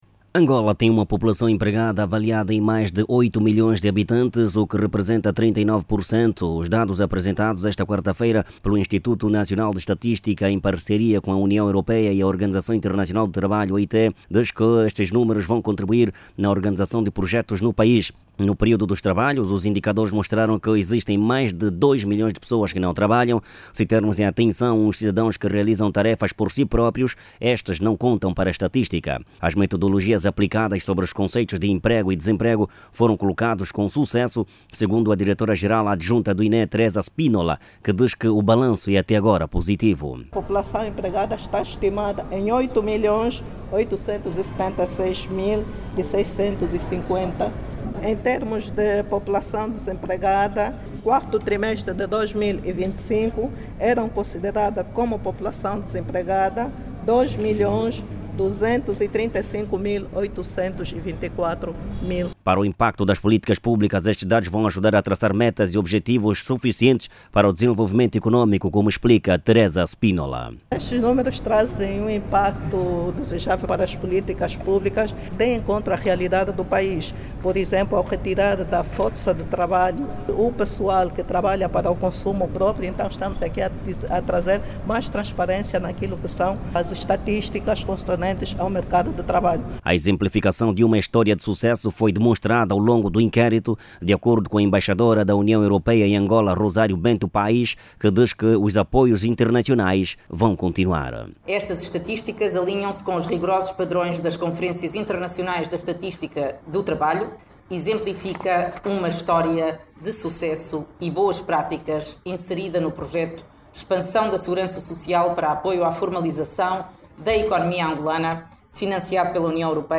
O Instituto Nacional de Estatística, apresentou os resultados da empregabilidade em Angola referente ao quarto trimestre de 2025. O documento indica que existem no país, mais de oito milhões de pessoas empregadas. O INE revela, no entanto, que neste inquérito não foram tidas em conta as pessoas que trabalham por conta própria, já que estas não contribuem para os Cofres do Estado com o pagamento do IRT, e outros impostos. Ouça o desenvolvimento desta matéria na voz do jornalista